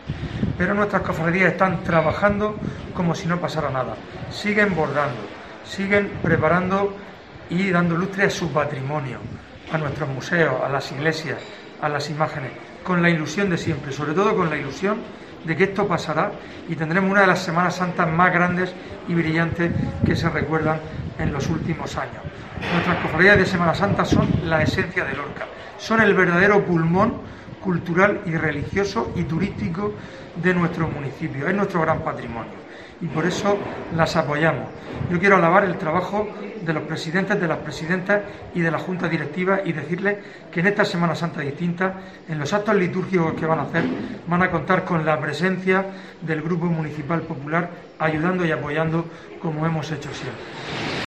Fulgencio Gil, portavoz del PP en Lorca